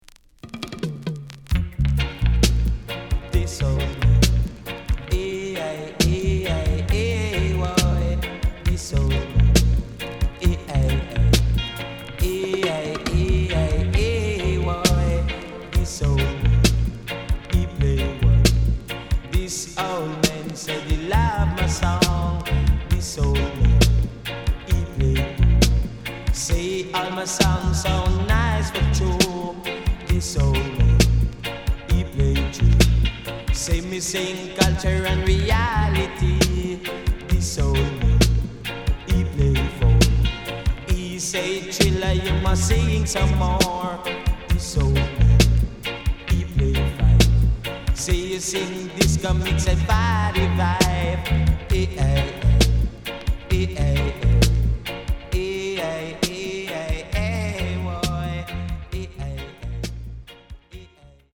HOME > DISCO45 [DANCEHALL]
SIDE A:所々チリノイズがあり、少しプチノイズ入ります。